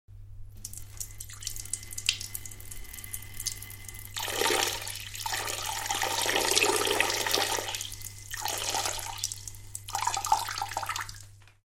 Звуки мочеиспускания
Звук пьяного мужчины, который промахивается и писает мимо унитаза